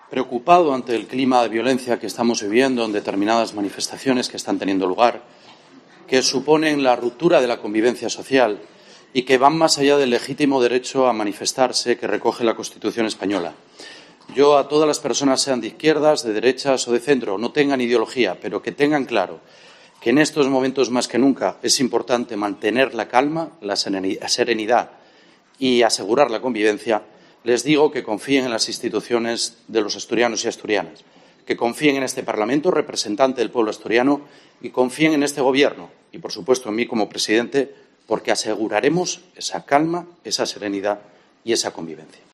Este duro cruce de acusaciones ha tenido lugar durante la sesión de "preguntas al presidente", celebrada este miércoles en el parlamento asturiano.